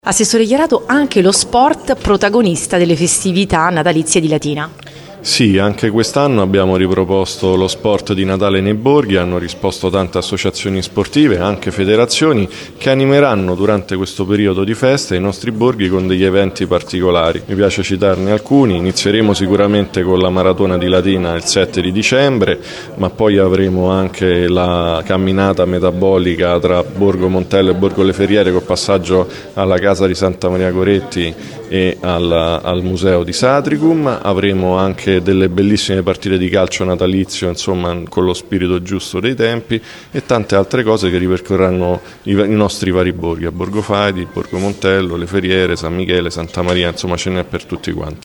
Anche lo sport protagonista del Natale nei borghi e non solo. L’assessore Chiarato